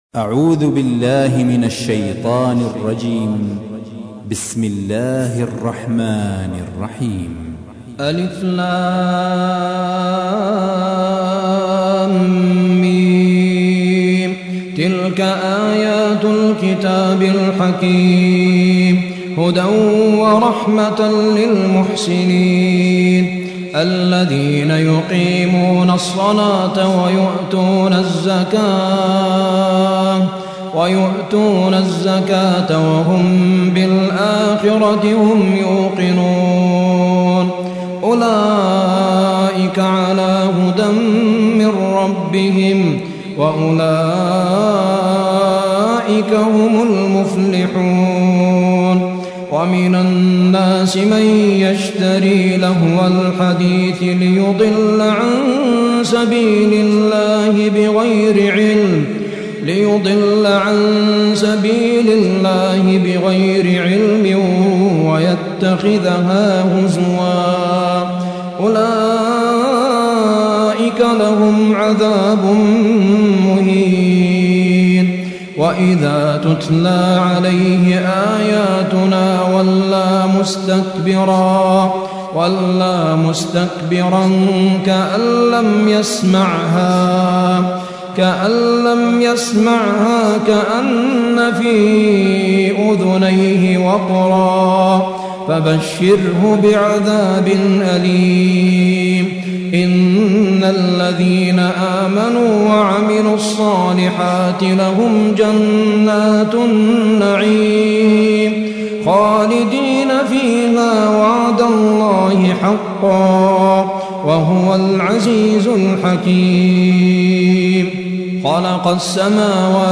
31. سورة لقمان / القارئ